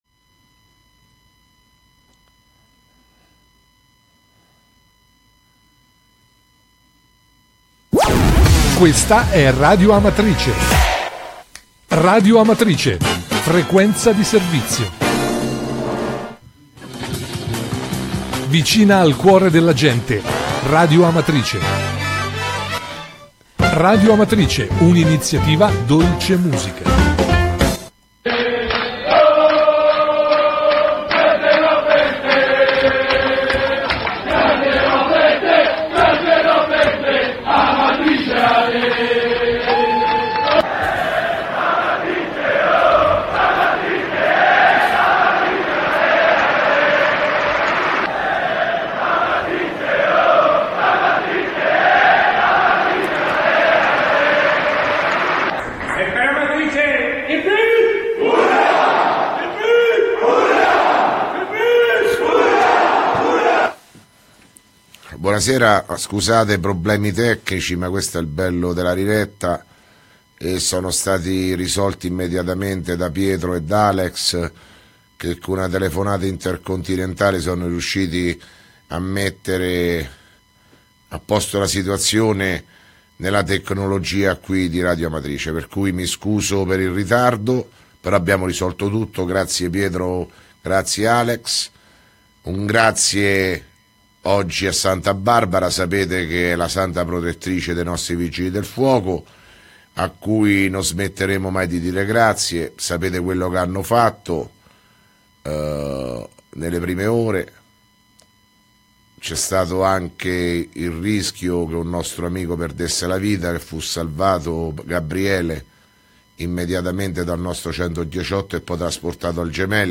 Di seguito il messaggio audio del Sindaco Sergio Pirozzi del 4 DICEMBRE 2017